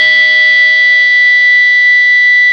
Index of /90_sSampleCDs/E-MU Producer Series Vol. 2 – More Studio Essentials/Composer/Plunge Guitars
FBACK B2.wav